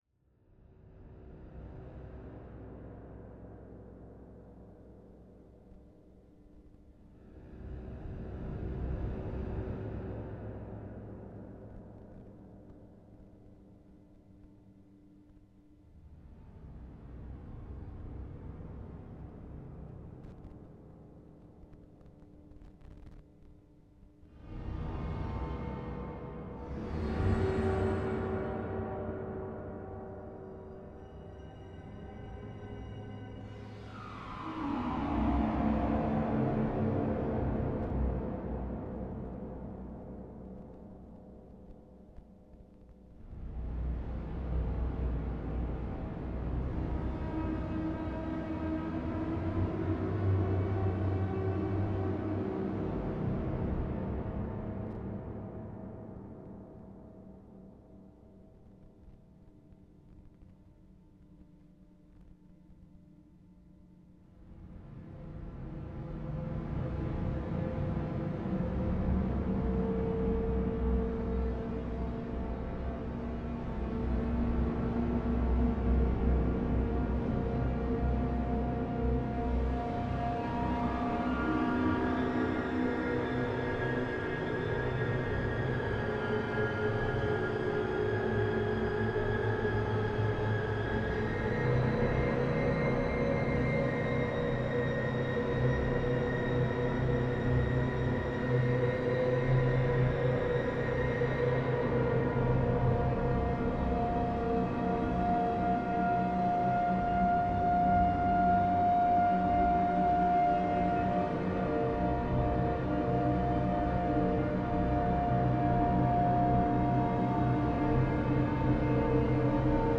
Music for Kalimba, Dulcimer & Modular Synthesizer
It's an original composition of mine where I experiment with using acoustic instruments for control a modular synthesizer.